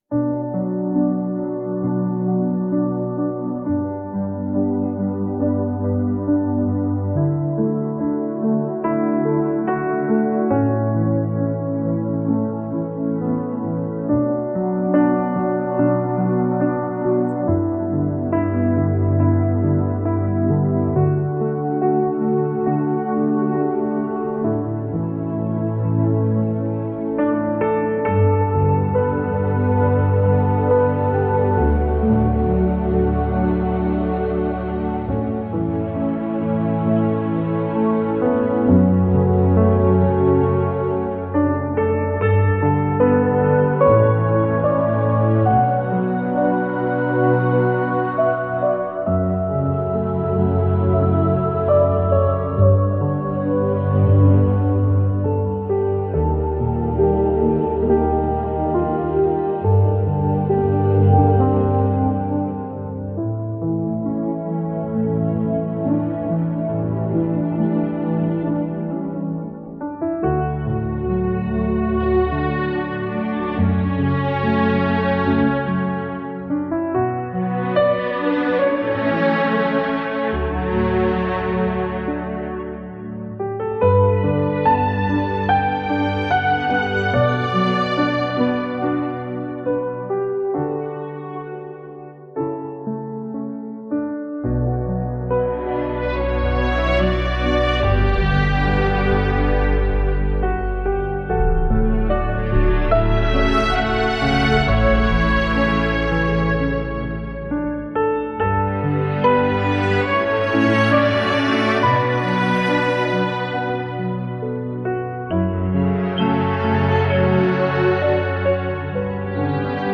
このサイトの音楽は、AI作曲ツールなどを使って制作しています。